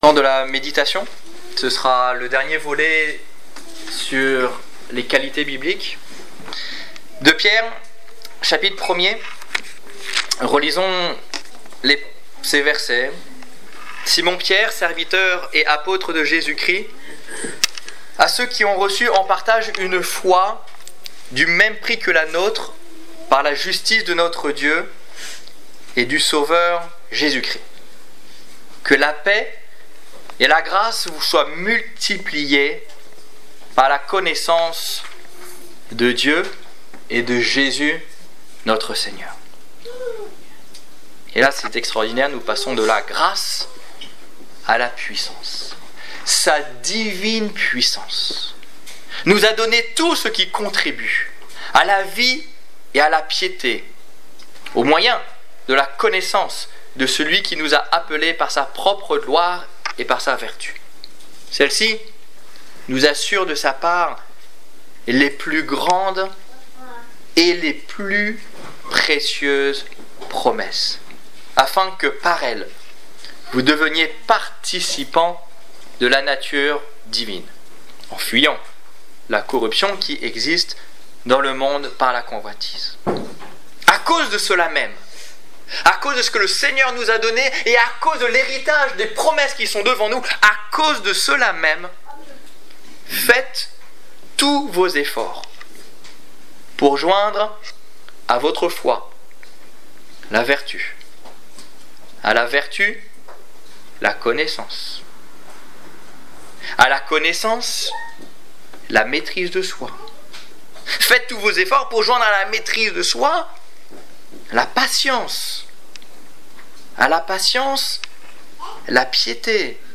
Culte du 15 novembre 2015 Ecoutez l'enregistrement de ce message à l'aide du lecteur